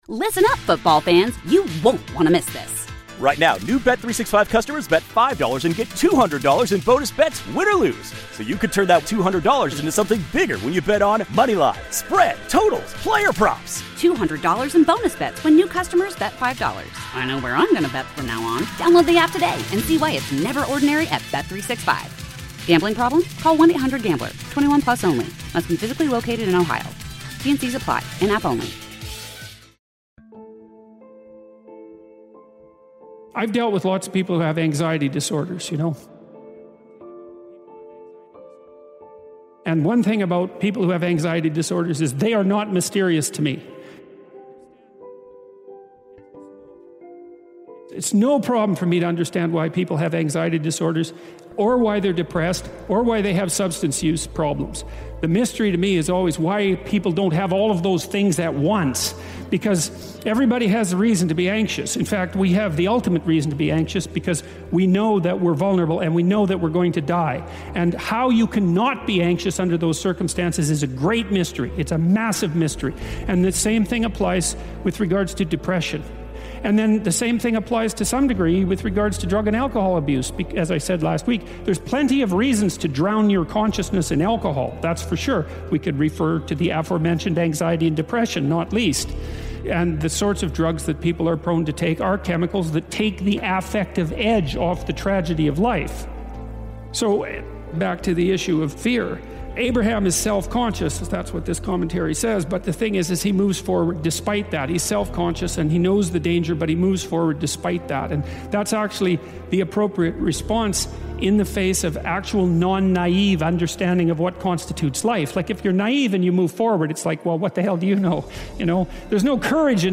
Facing Fear, Moving Forward, Finding Courage - Powerful Motivational Speech
We’ve crafted a dynamic blend of Motivational Speeches from renowned motivational speakers, uplifting messages, and Empowering Words designed to strengthen your Positive Mindset and drive Success.